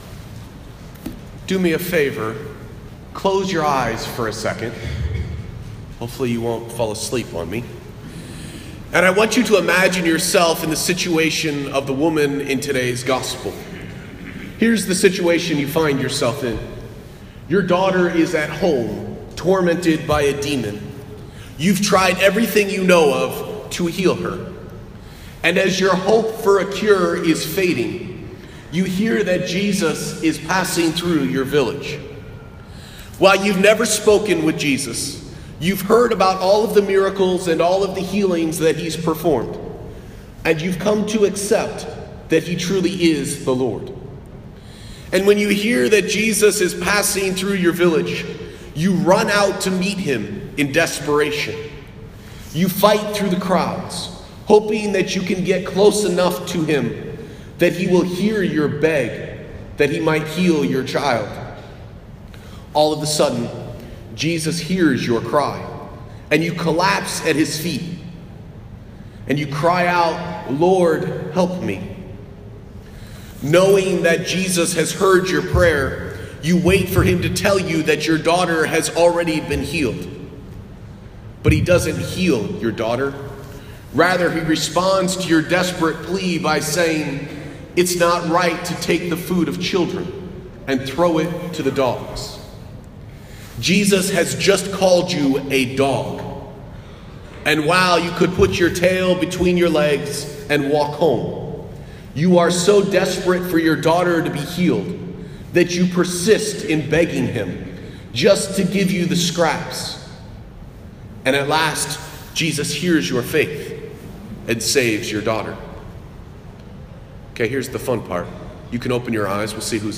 Posted in Homily, Uncategorized